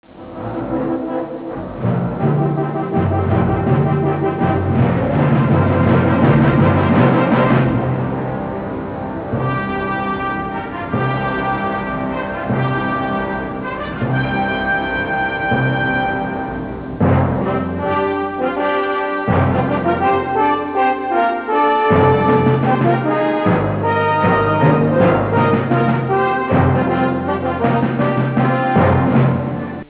Melodica, ma ricca di possenti marce romane
Original track music